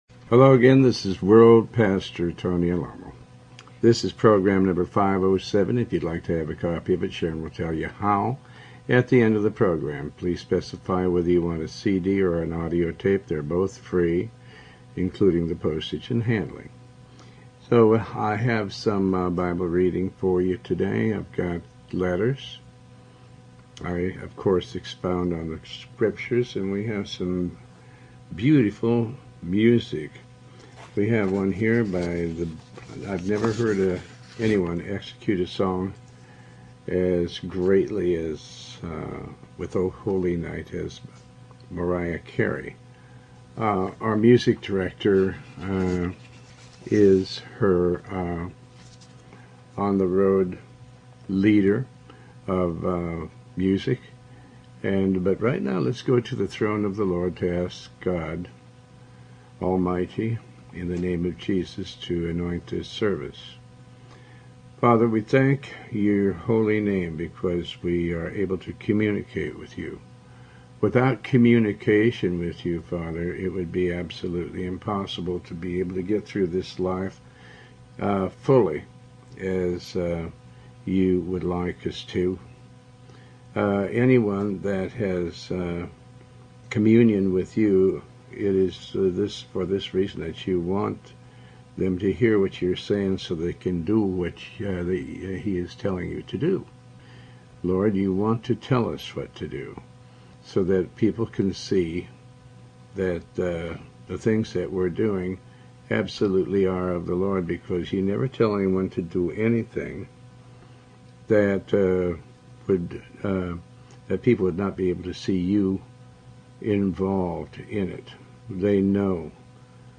Talk Show Episode, Audio Podcast, Tony Alamo and Program 507 on , show guests , about Faith,pastor tony alamo,Tony Alamo Christian Ministries,Bible Study,sermon, categorized as Health & Lifestyle,History,Love & Relationships,Philosophy,Psychology,Christianity,Inspirational,Motivational,Society and Culture